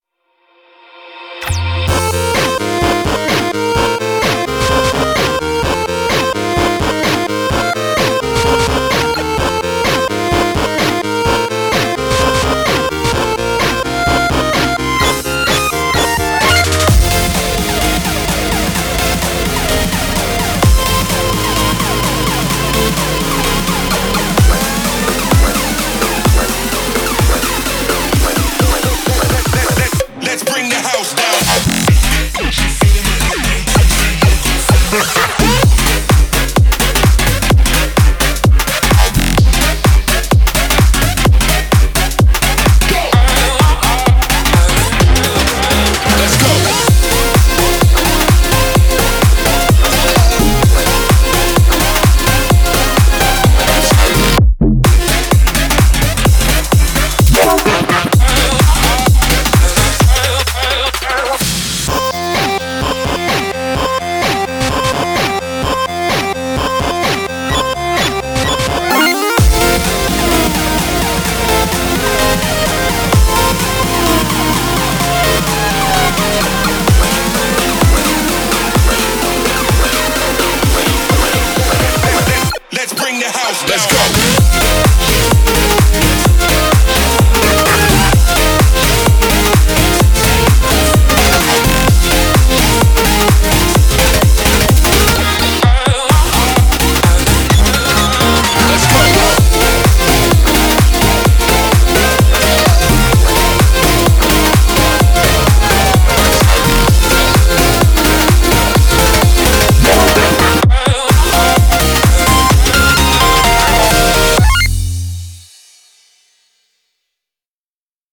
BPM128
Audio QualityPerfect (High Quality)
Is anyone up for some shuffling with 8-bit?